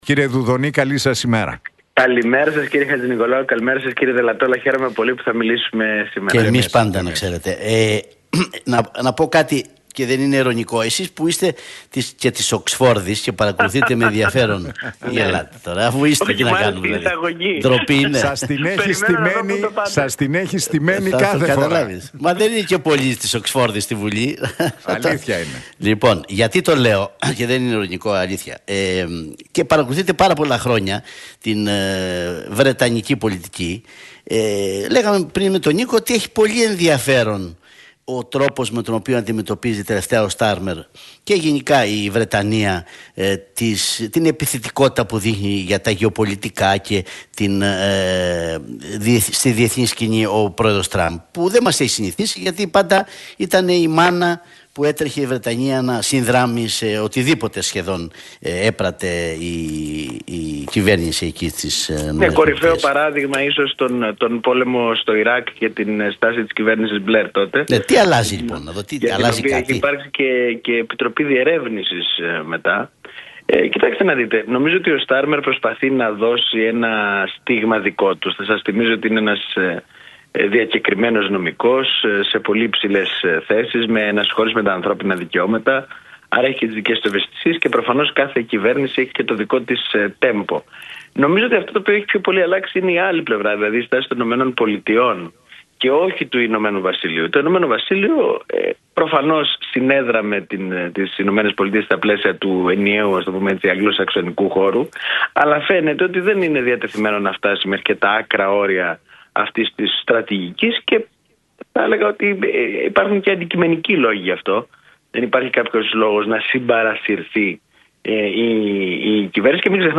Δουδωνής στον Realfm 97,8: Πρέπει να τηρείται η μυστικότητα στα όργανα – Η λογική των διαρροών είναι μια προσωπική λογική